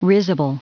Prononciation du mot risible en anglais (fichier audio)
Prononciation du mot : risible